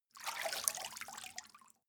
Gemafreie Sounds: Schritte